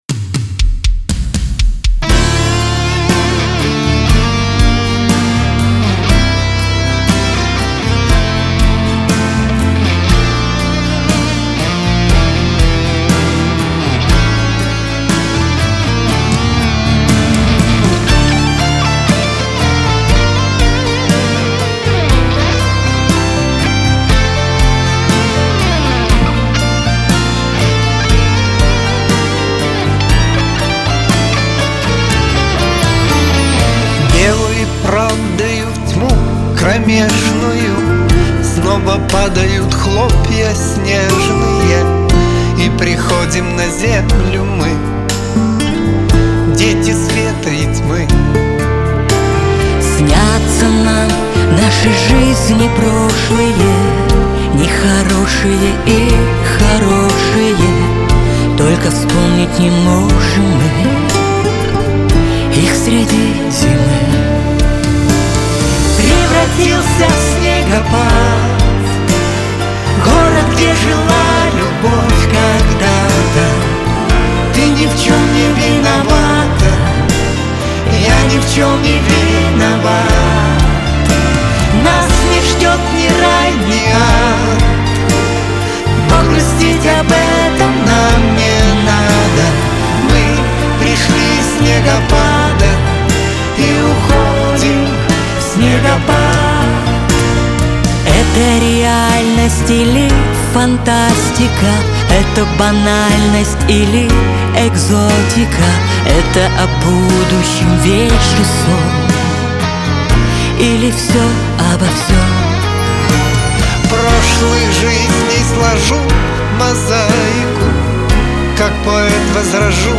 pop
Лирика , дуэт